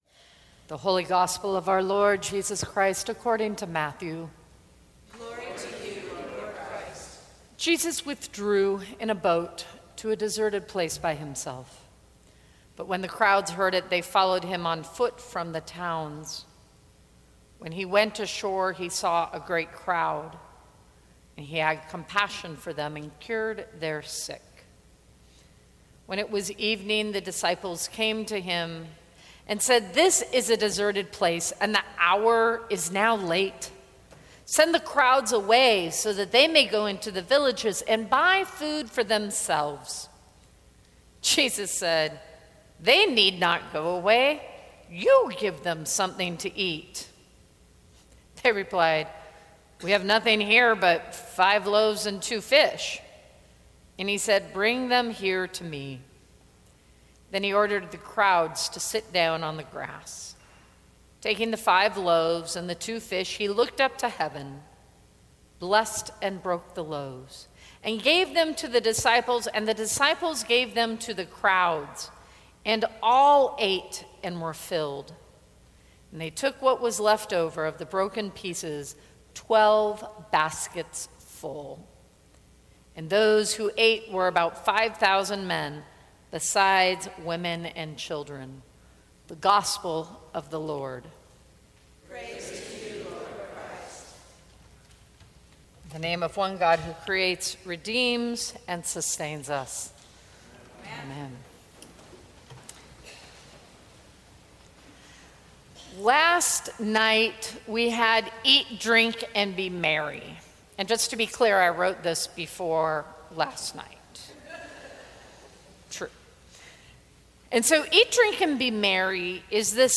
Sermons from St. Cross Episcopal Church Feasts are Meant to be Shared Oct 23 2016 | 00:16:59 1x 00:00 / 00:16:59 Subscribe Share Apple Podcasts Spotify Overcast RSS Feed Share Link Embed